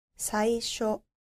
• saisho